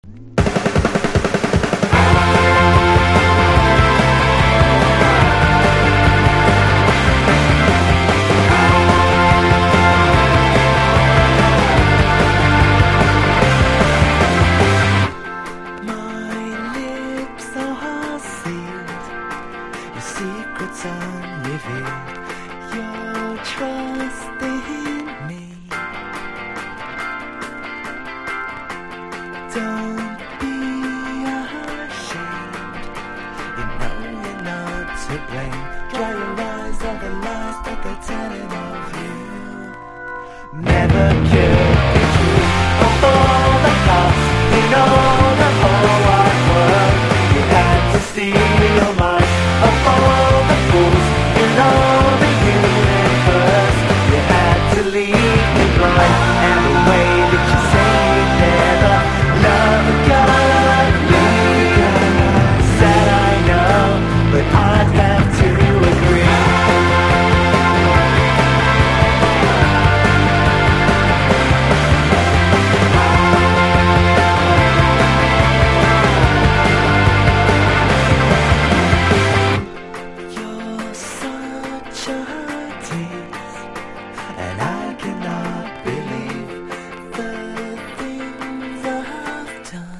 とことんキャッチーなメロディとガッツ溢れる疾走感でツボを抑えた傑作!!
（オリジナル盤）：90'S ROCK